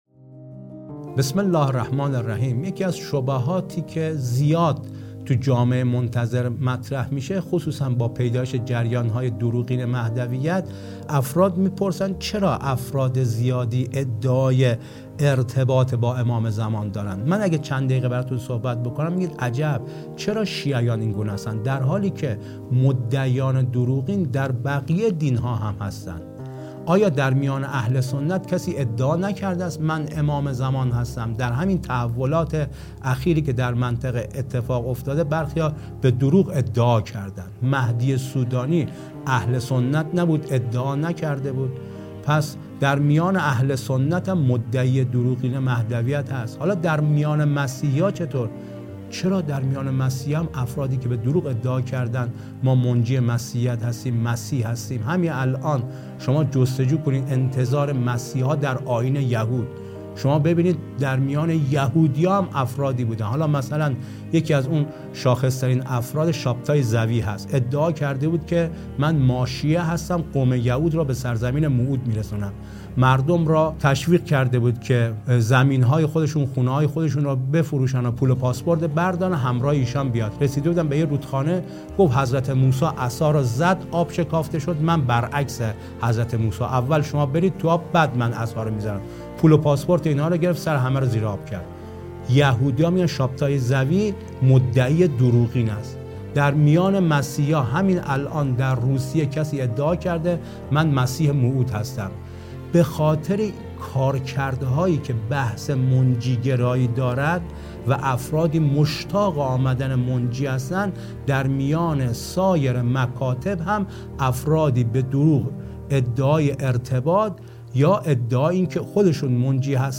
گفتگویی تخصصی